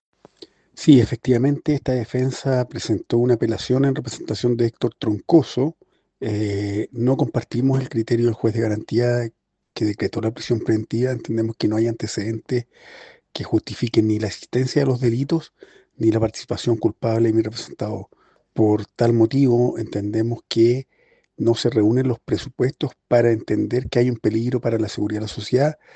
El defensor penal privado